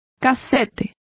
Complete with pronunciation of the translation of cassette.